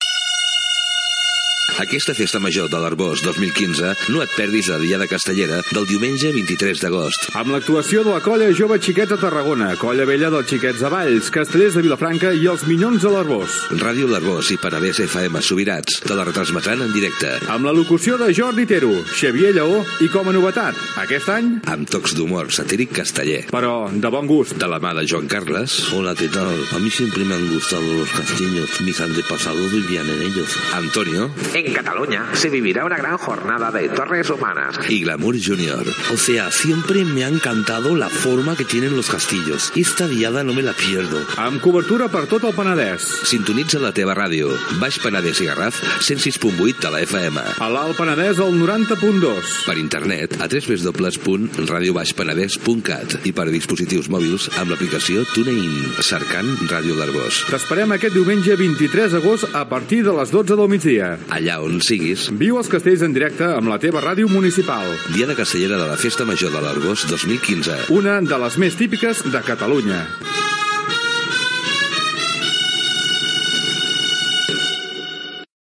Promoció de la Diada Castellera de la Festa Major de l'Arboç